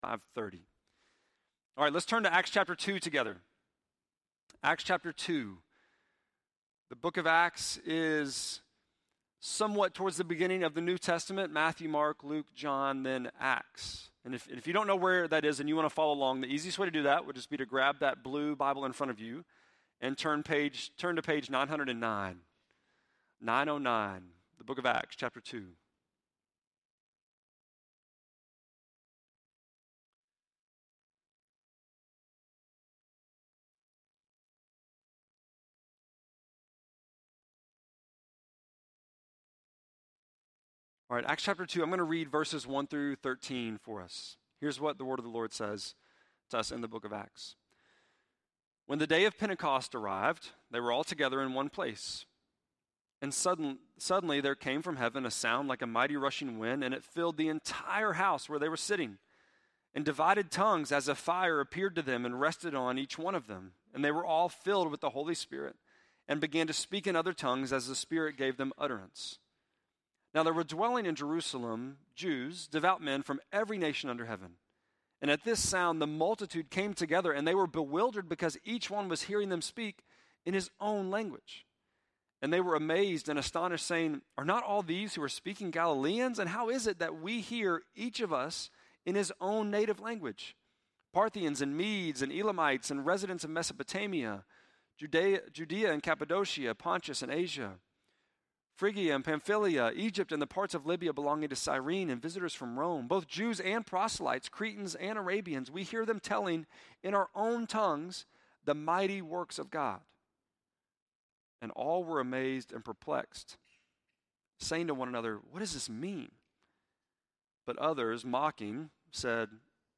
6.9-sermon.mp3